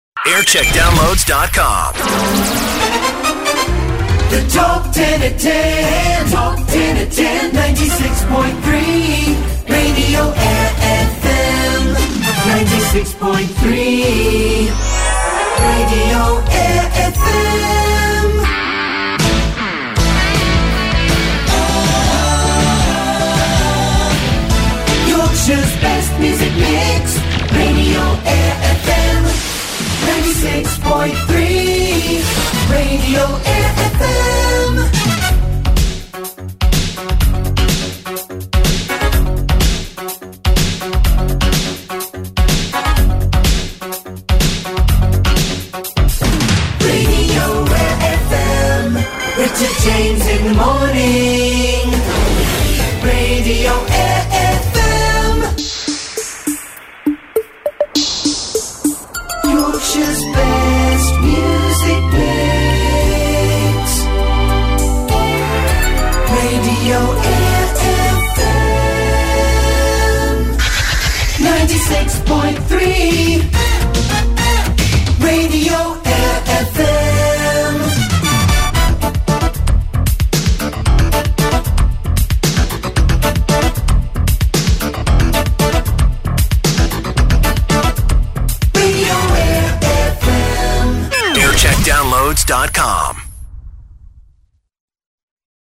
Jingle Montage